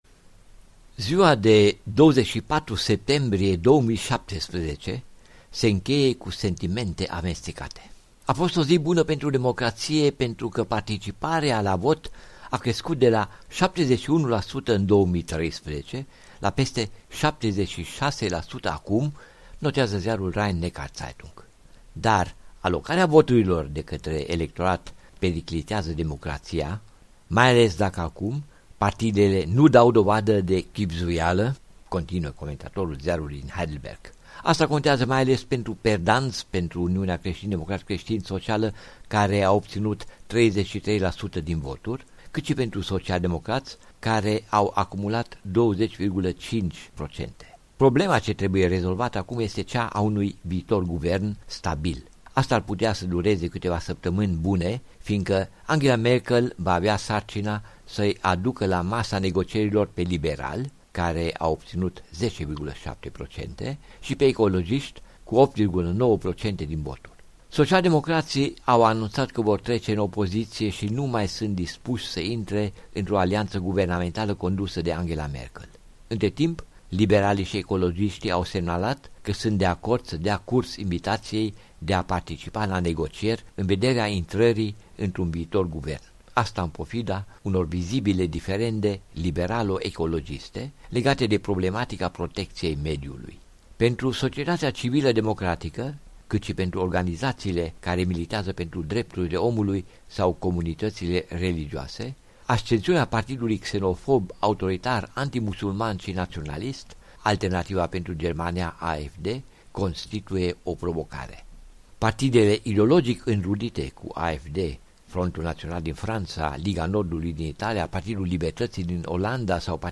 Corespondența zilei de la Berlin